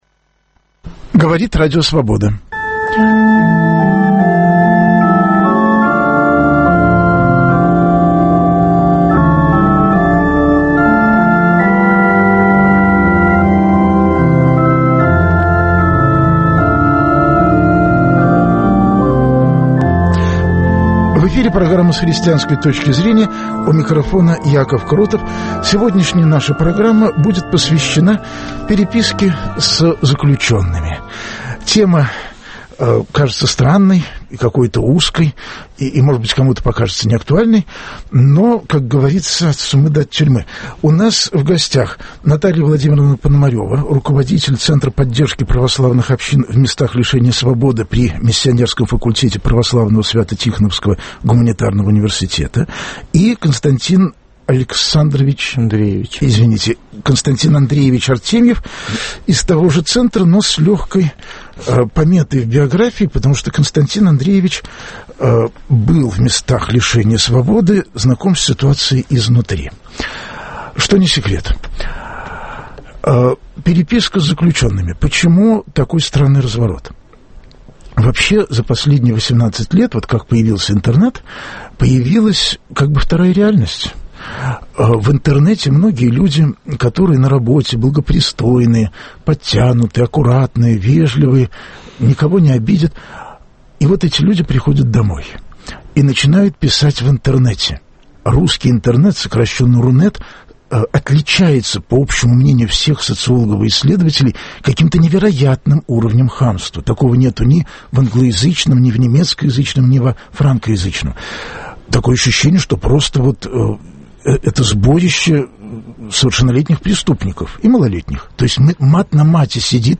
Гости программы - один пишет письма, другой стал православным в тюрьме и теперь работает в тюремном православном братстве.